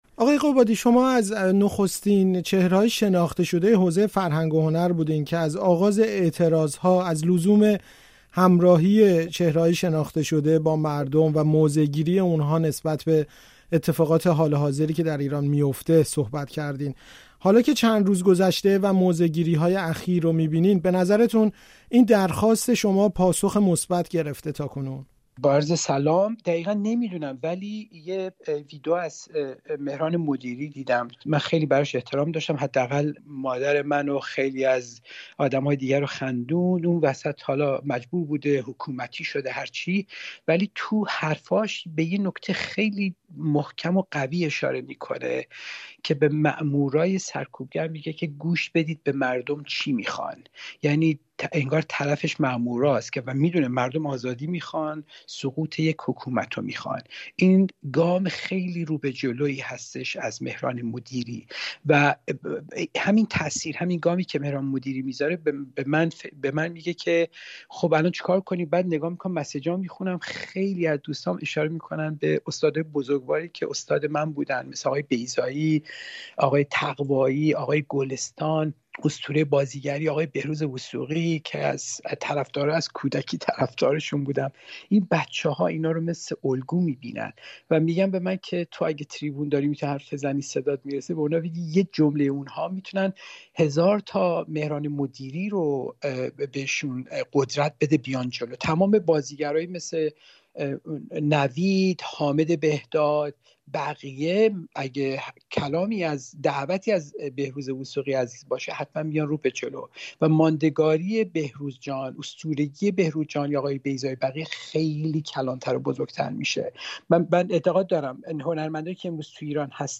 آرزو داشتم جای ژینا باشم؛ گفت‌وگو با بهمن قبادی